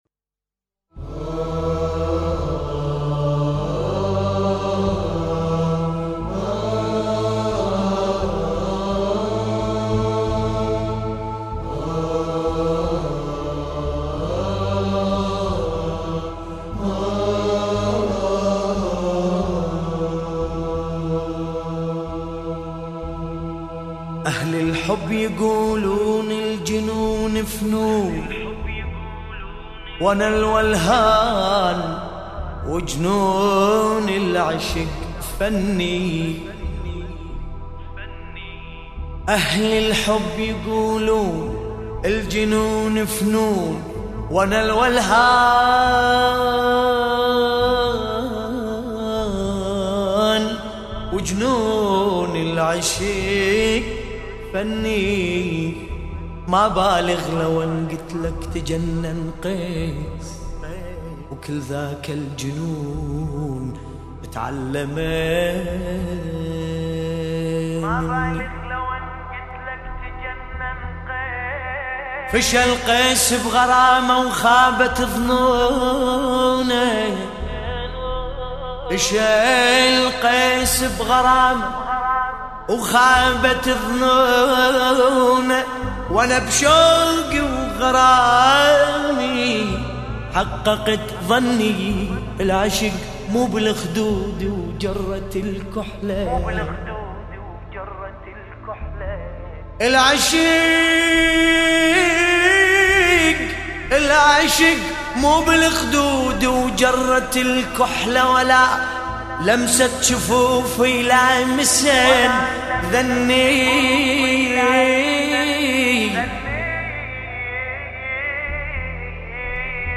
1427 مولودی